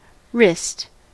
Ääntäminen
Ääntäminen US : IPA : [ɹɪst] Haettu sana löytyi näillä lähdekielillä: englanti Käännös Substantiivit 1.